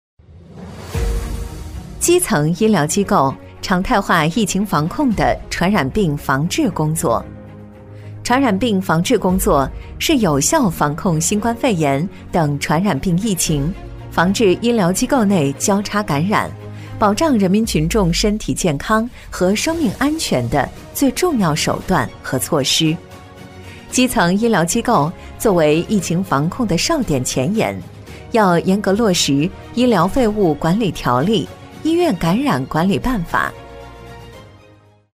医疗医院宣传配音【海豚配音】
女18-医疗专题【传染病防控》- 正式大气
女18-医疗专题【传染病防控》- 正式大气.mp3